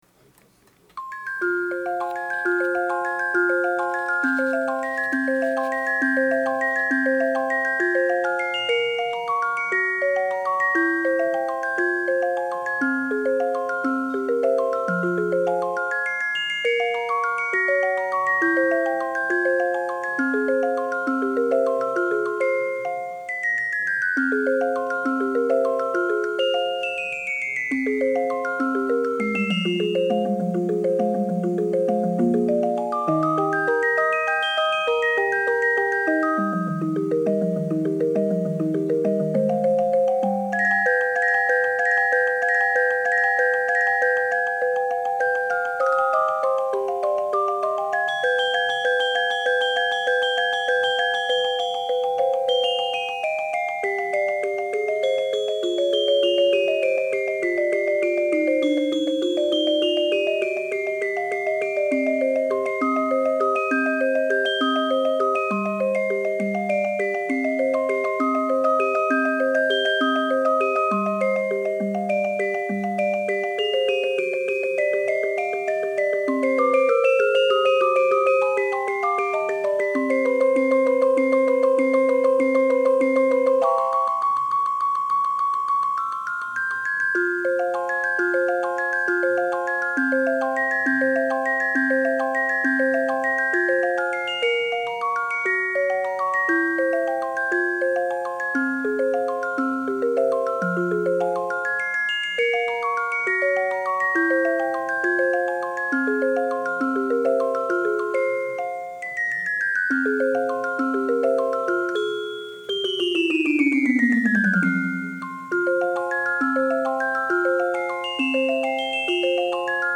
電子オルゴール
私が作ったオルゴール